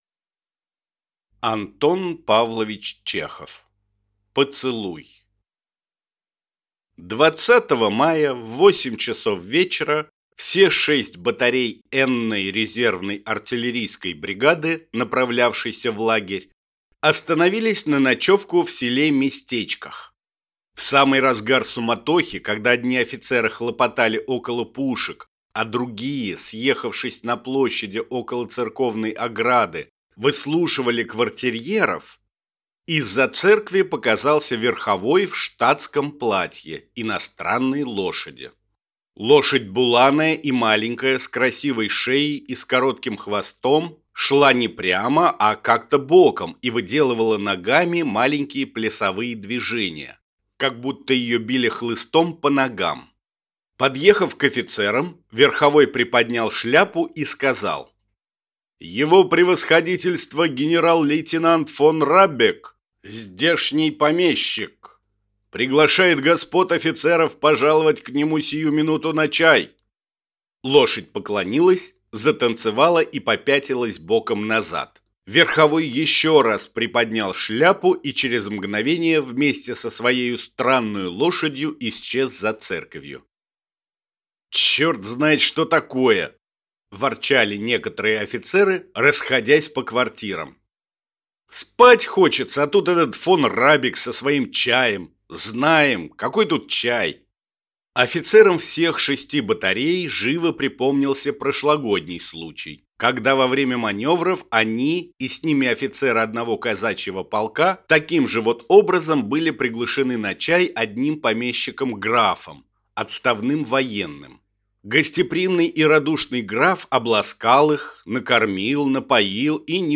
Аудиокнига Поцелуй | Библиотека аудиокниг